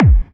drum-hitwhistle.ogg